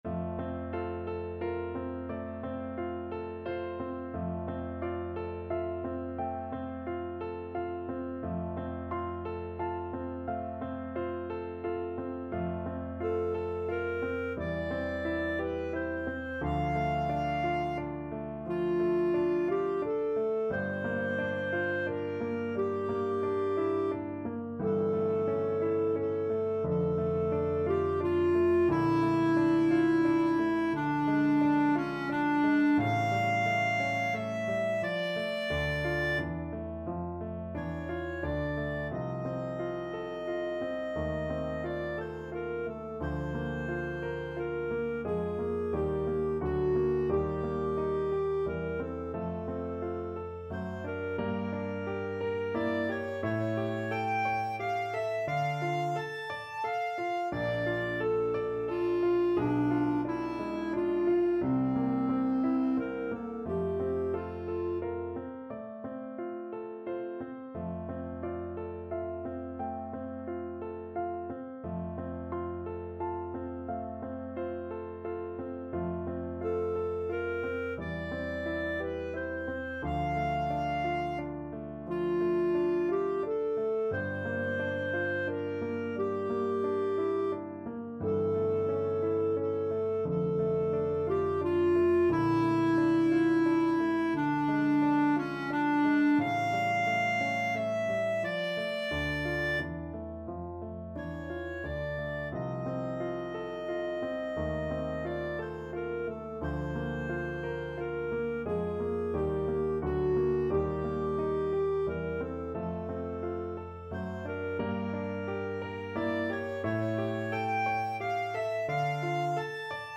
Classical Fanny Mendelssohn Die Mainacht from 6 Lieder, Op.9 Clarinet version
F major (Sounding Pitch) G major (Clarinet in Bb) (View more F major Music for Clarinet )
~ = 88 Andante
6/4 (View more 6/4 Music)
Clarinet  (View more Easy Clarinet Music)
Classical (View more Classical Clarinet Music)
f_mendelssohn_6_lieder_op9_die_mainacht_CL.mp3